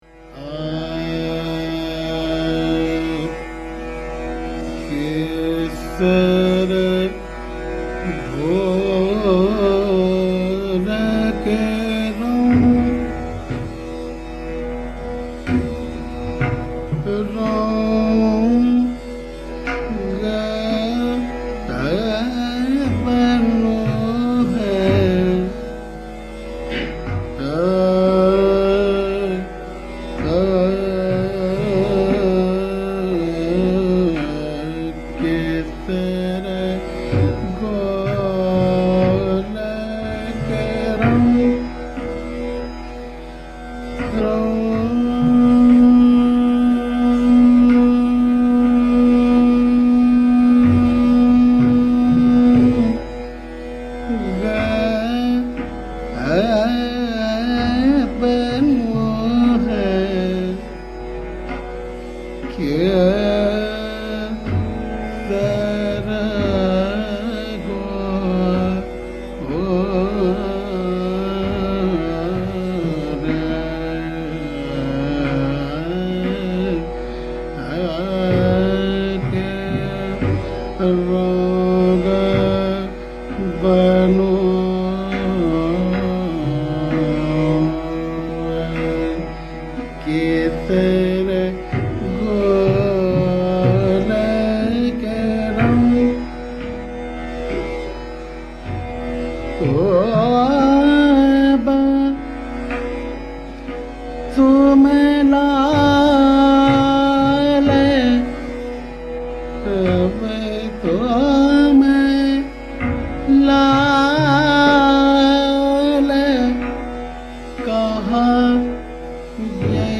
The Senior Dagar Brothers, N. Moinuddin and N. Aminuddin, sing an old dhamar lyric in hori style: